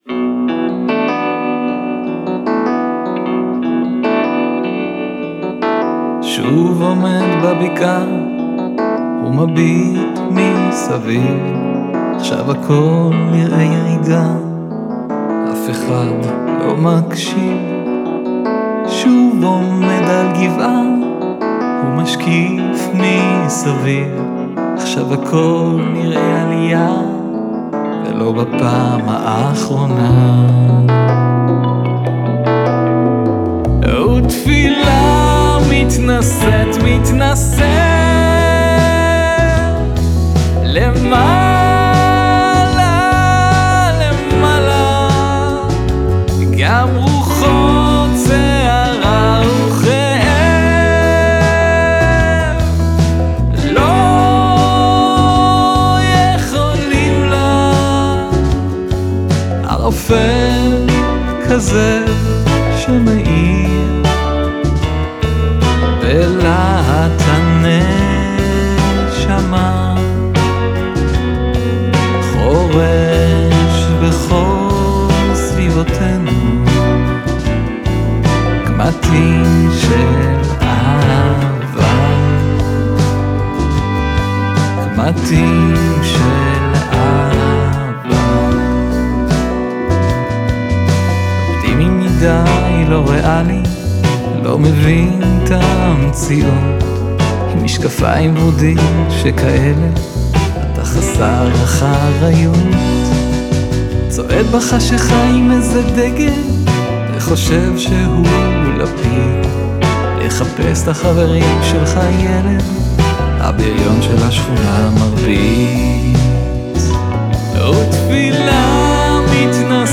הוצמד לחן מרגש ומקורי